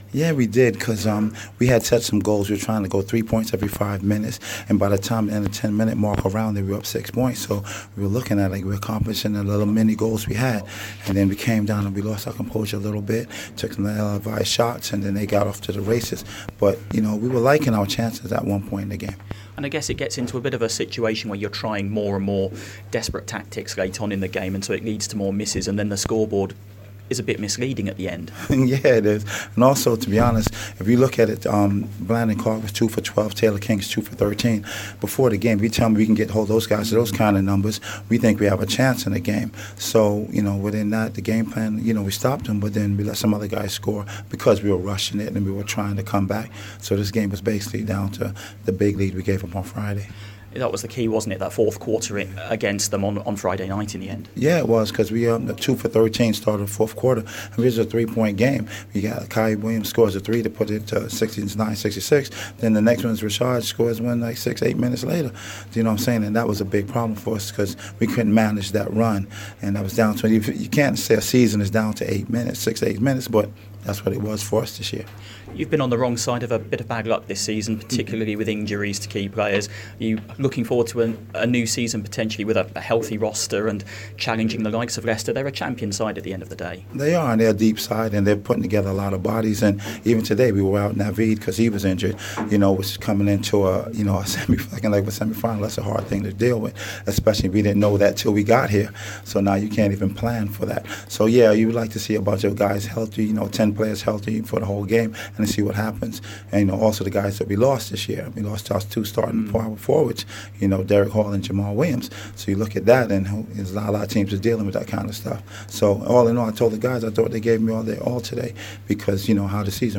ANALYSIS